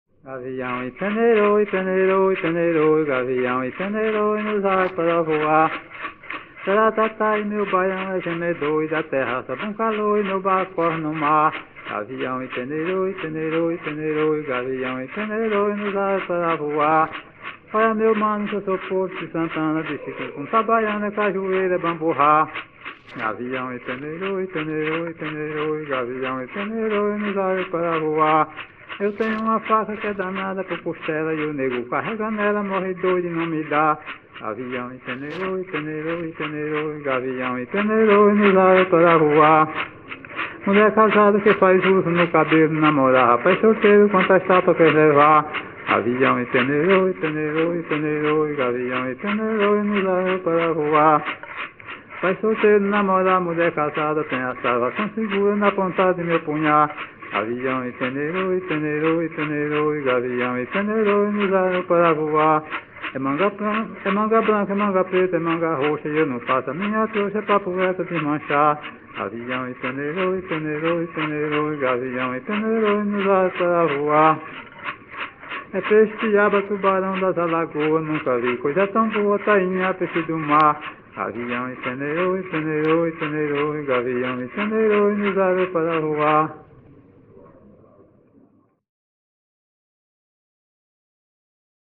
Coco -""Gavião""